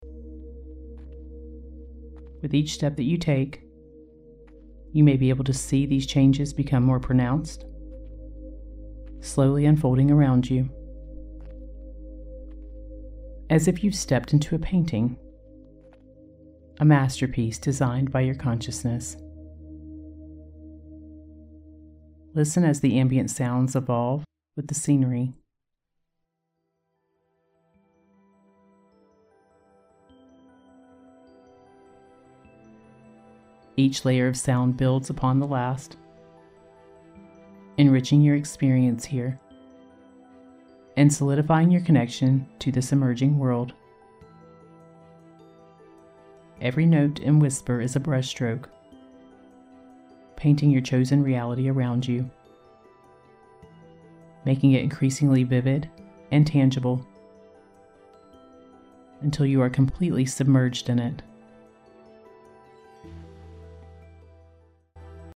‘Step Through Your Astral Gateway’ 3D Audio Meditation (with priming sound sessions included)
Featuring an alpha wave track for enhanced focus and creativity, along with neuro-editing to optimize your meditative experience, this package is designed to transform your perception and guide you through powerful reality shifts. The session combines immersive soundscapes with powerful suggestions to unlock your manifestation potential. (Headphones required for the 3D sound experience.)
• 3D Spatial Audio Experience: Feel enveloped by the rich layers of sound with 3D audio that places you at the center of your own cognitive transformation.
• Alpha Wave Integration: Alpha waves are integrated throughout the session to enhance your relaxation and increase your mental clarity.